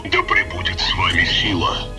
sentry_build.wav